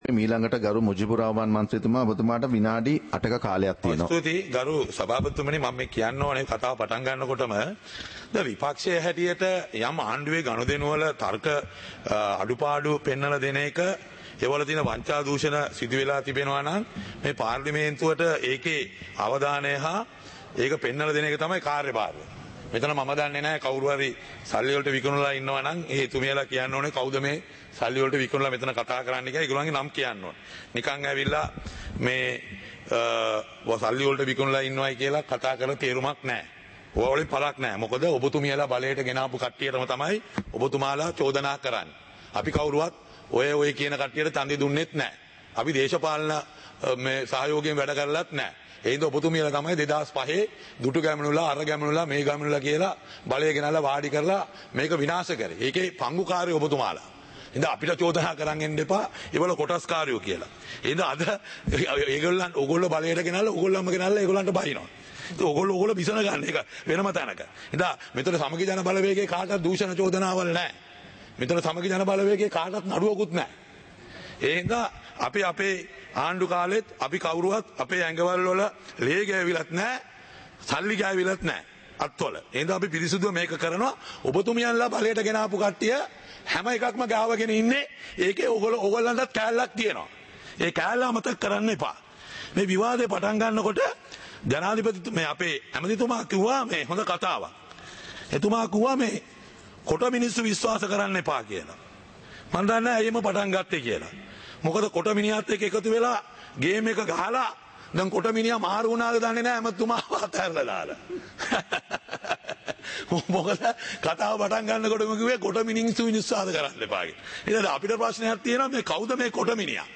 சபை நடவடிக்கைமுறை (2026-02-20)